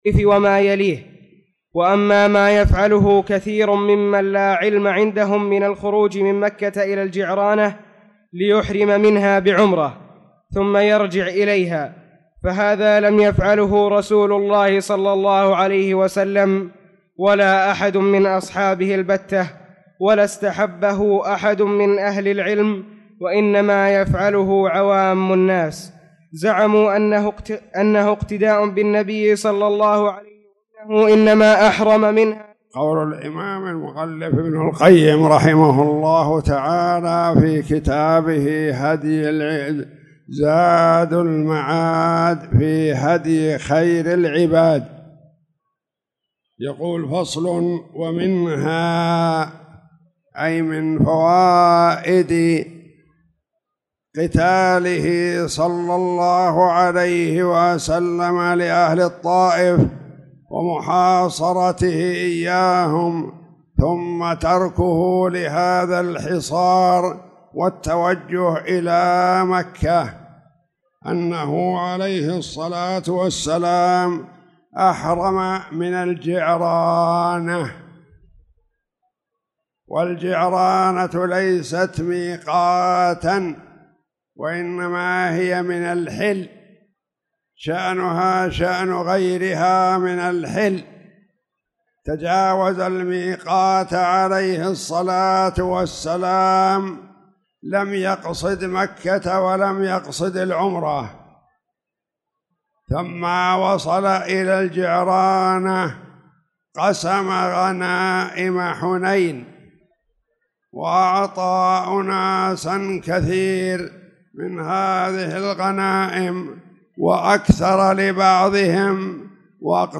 تاريخ النشر ٦ شعبان ١٤٣٧ هـ المكان: المسجد الحرام الشيخ